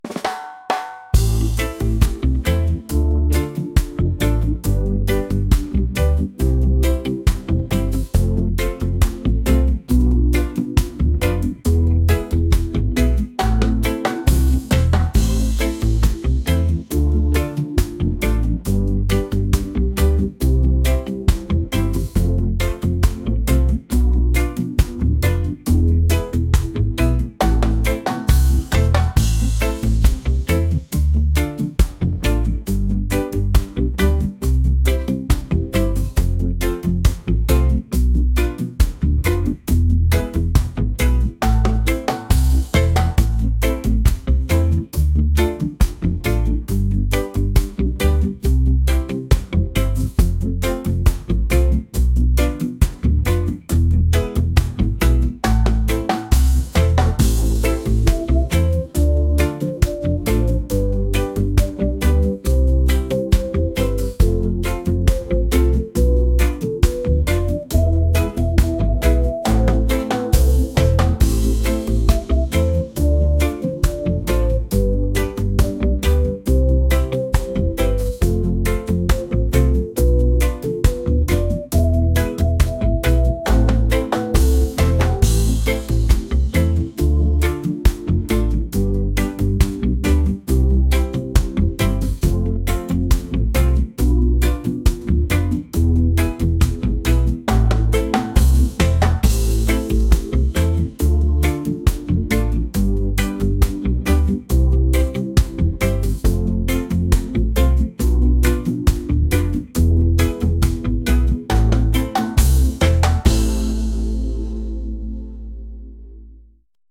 reggae | soul & rnb